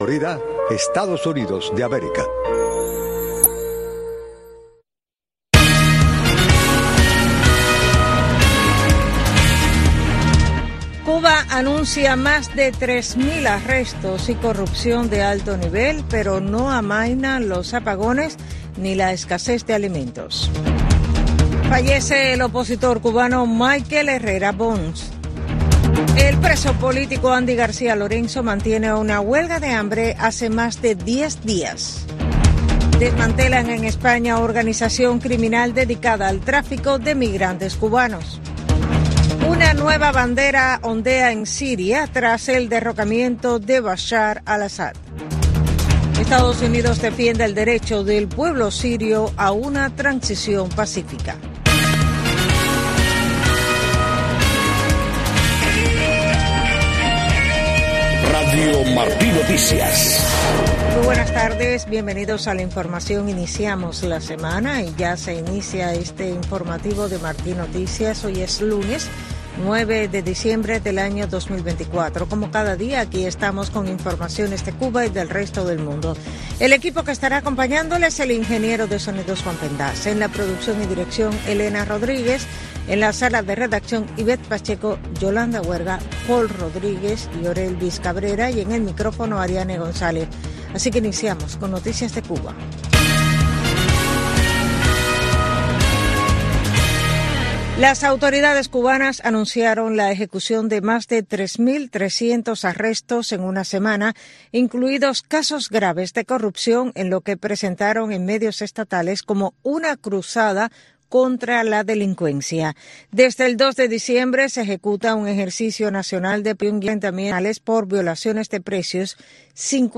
Noticiero de Radio Martí 12:00 PM | Primera media hora